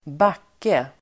Uttal: [²b'ak:e]